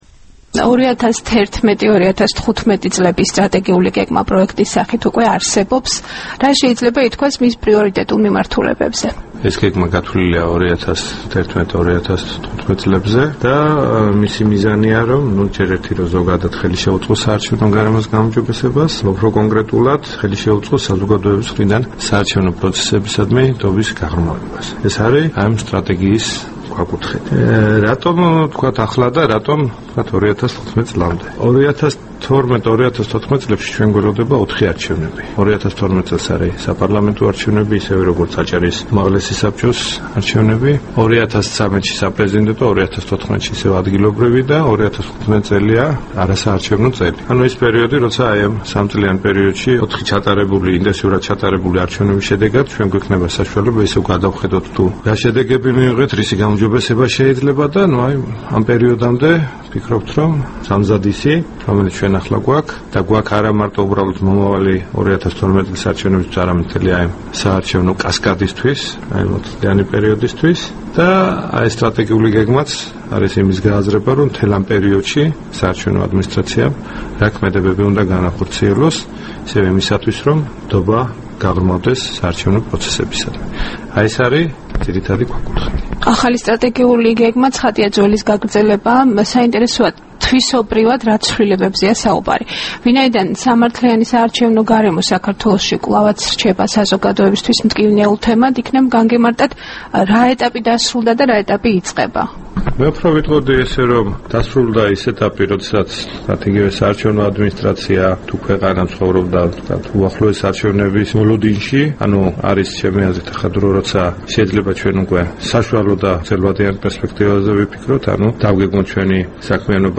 ინტერვიუ
საქართველოს ცენტრალურმა საარჩევნო კომისიამ განსახილველად გამოაქვეყნა 2011 - 2015 წლების სტრატეგიული გეგმის პროექტი, რომელიც საბოლოო დოკუმენტის სახით დაახლოებით ერთ თვეში დამტკიცდება. პროექტის მიზანი საარჩევნო პროცესებისა და არჩევნების შედეგებისადმი საზოგადოების ნდობის გაღრმავებაა. როგორ აისახა ამ ამოცანის უზრუნველყოფის პრინციპები სტრატეგიული გეგმის პროექტში ცენტრალური საარჩევნო კომისიის თავმჯდომარესთან, ზურაბ ხარატიშვილთან ინტერვიუში მოისმენთ.
ინტერვიუ ზურაბ ხარატიშვილთან